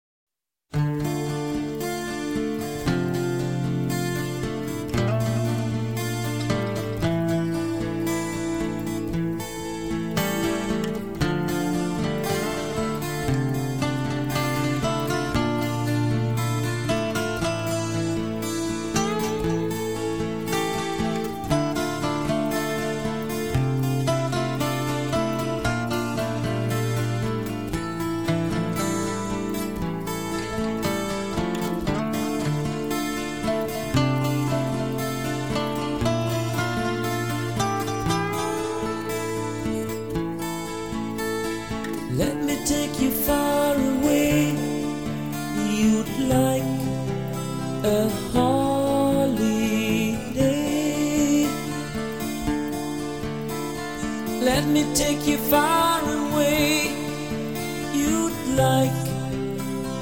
Рок
Самые известные баллады